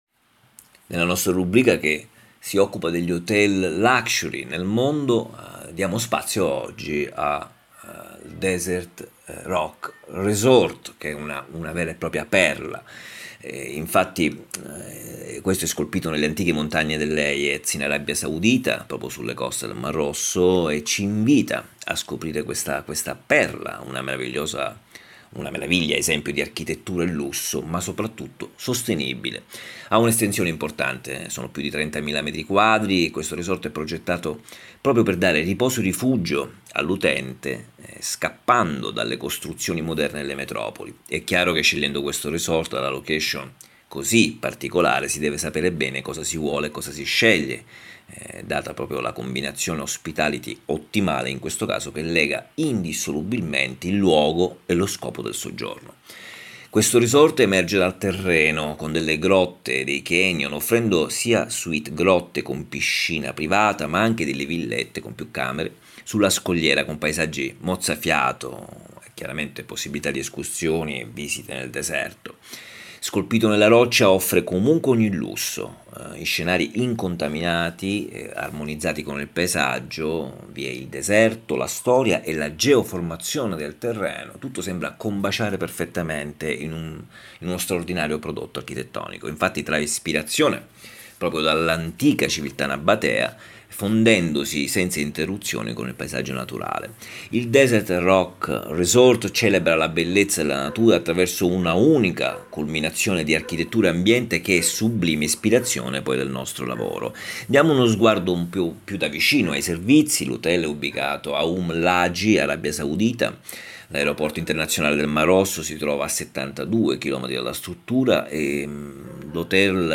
AUDIO ARTICOLO DESERT RESORT HOTEL